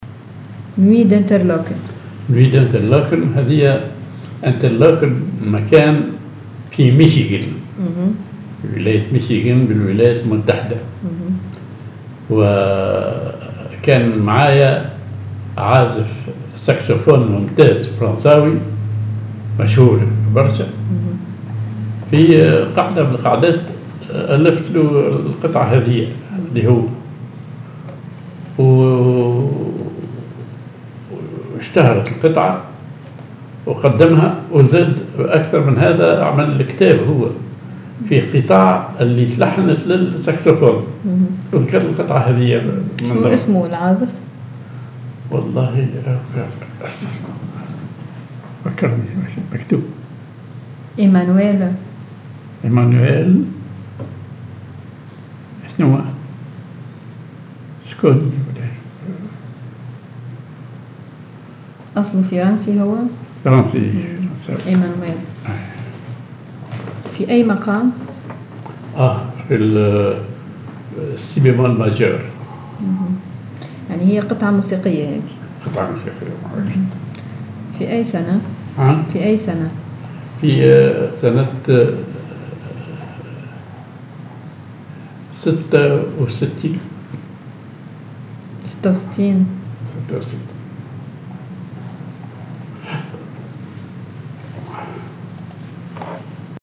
Maqam ar بوسلك دوكاه (ري صغير)
معزوفة